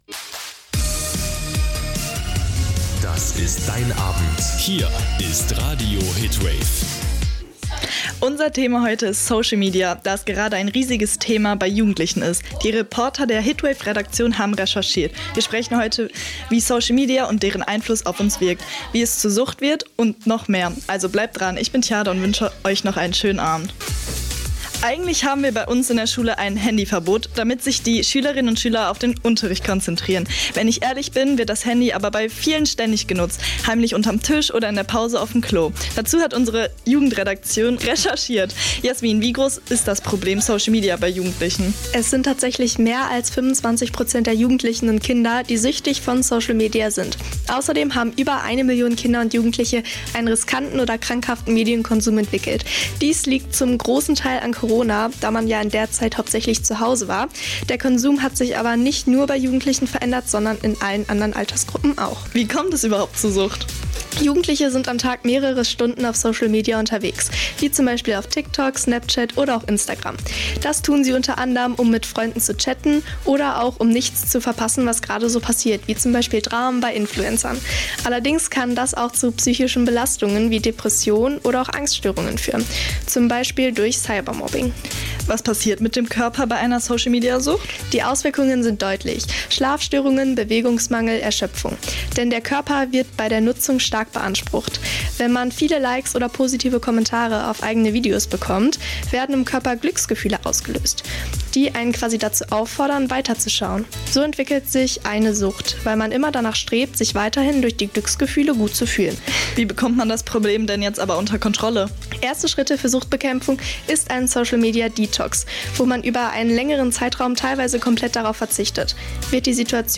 Sendung der Hitwave-Jugendredaktion zum Thema Social Media
November 2025 Die Hitwave Jugendredaktion widmet sich in ihrer aktuellen Sendung dem Thema Social Media und dessen Auswirkungen. Die jungen Redakteure beleuchten, wie soziale Netzwerke unseren Alltag, unsere Kommunikation und unser Selbstbild beeinflussen.